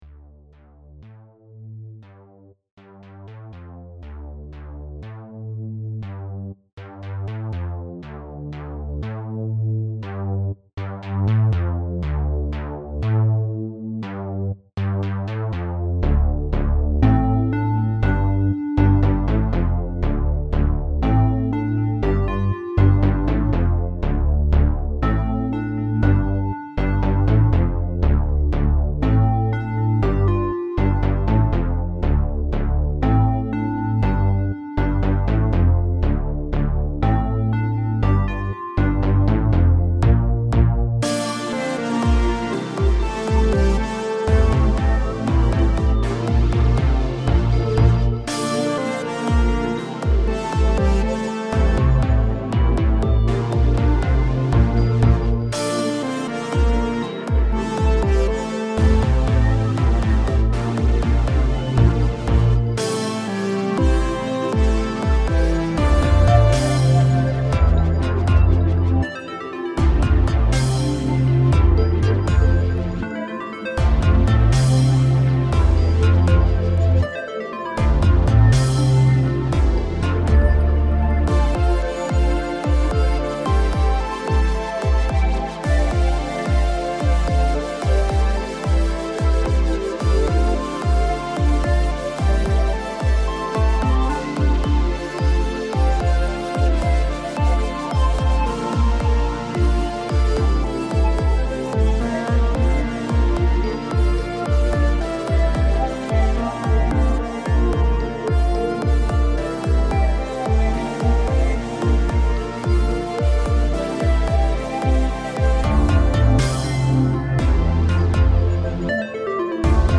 ProgRockBallad
모든 공연은 신시사이저 톤에서 선택되었습니다.